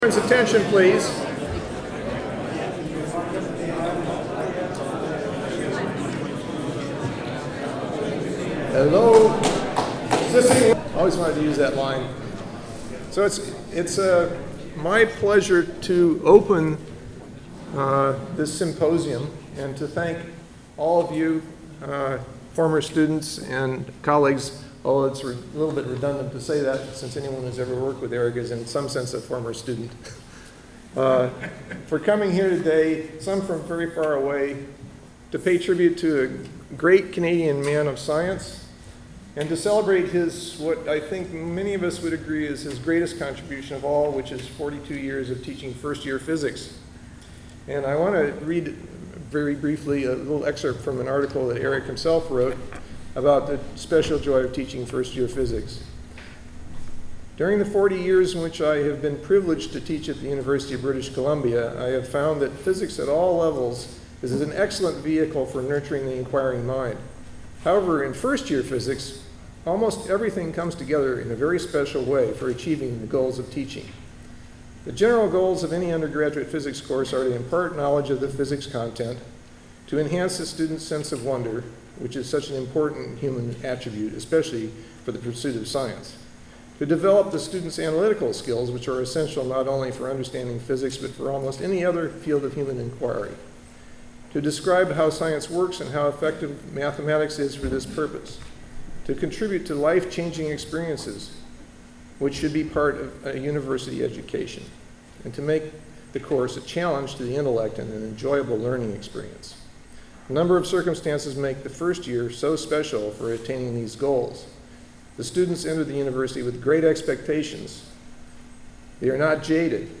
Vogt Symposium